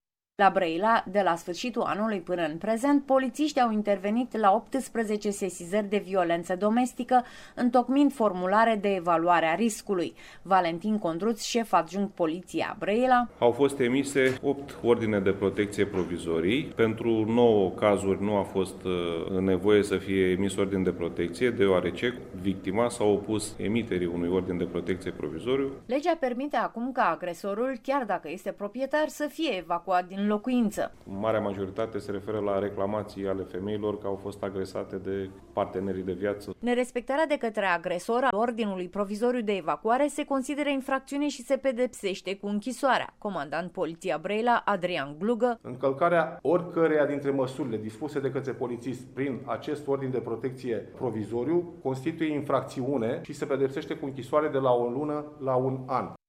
Poliţiştii au posibilitatea să acţioneze mai prompt în cazurile de violenţă domestică, după ce, la sfârşitul lunii trecute, a intrat în vigoare un ordin privind modalitatea de gestionare a unor astfel de situaţii. La Brăila s-a acţionat, deja, în câteva cazuri de acest fel. Corespondenta noastră